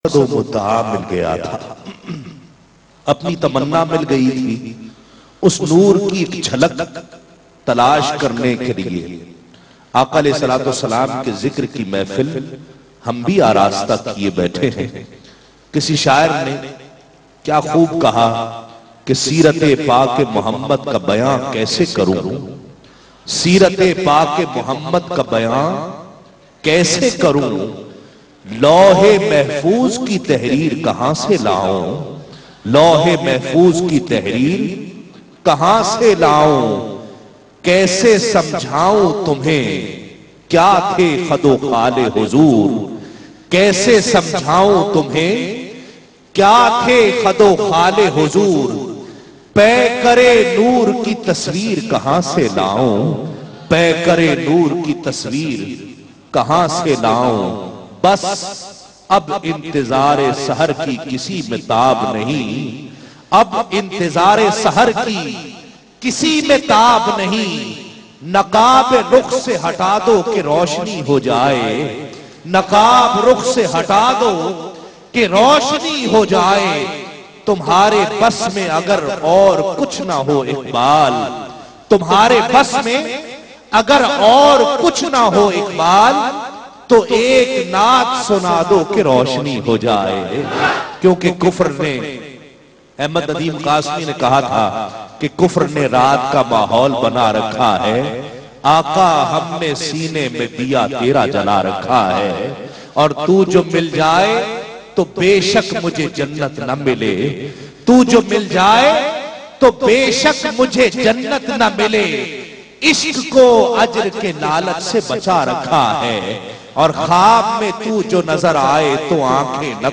URDU NAAT
Naat in a Heart-Touching Voice